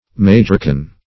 Search Result for " majorcan" : The Collaborative International Dictionary of English v.0.48: Majorcan \Ma*jor"can\, prop. a. Of or pertaining to Majorca.
majorcan.mp3